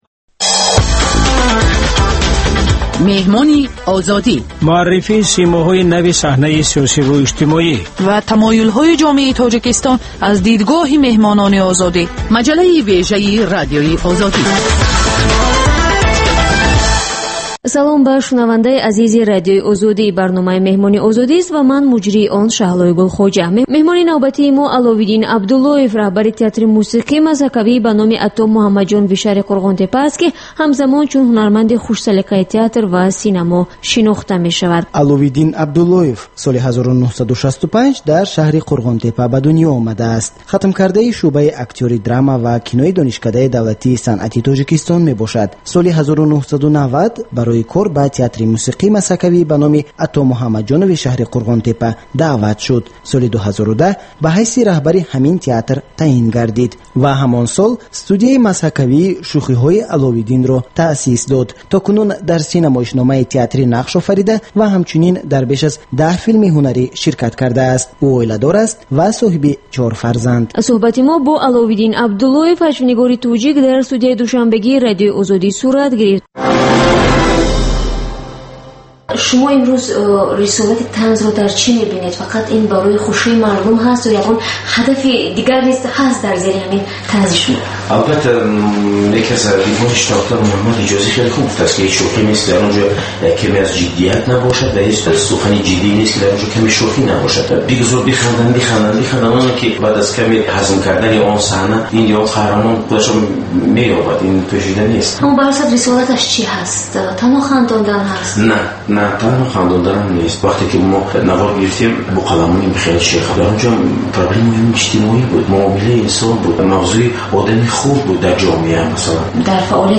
Сӯҳбати ошкоро бо чеҳраҳои саршинос ва мӯътабари Тоҷикистон бо пурсишҳои сангин ва бидуни марз.